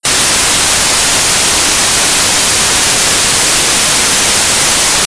blues.00000.wav